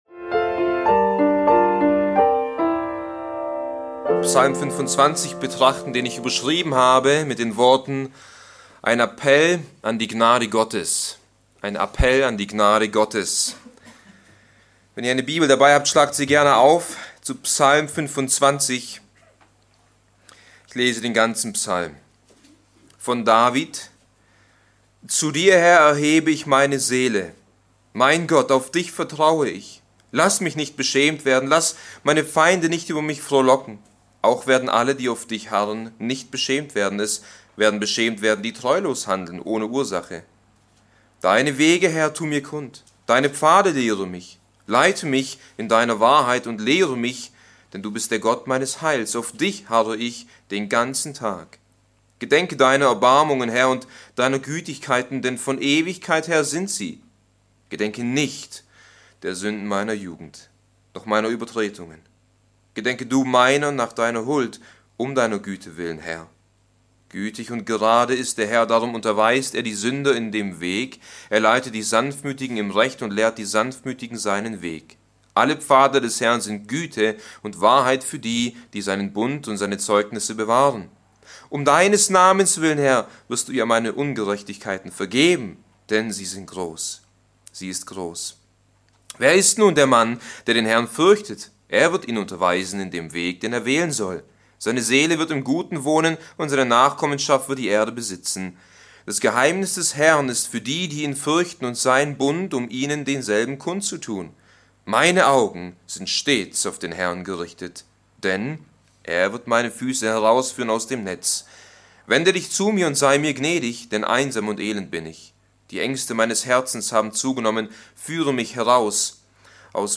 Allein aus Gnade - Bibeltreue Predigten der Evangelisch-Baptistischen Christusgemeinde Podcast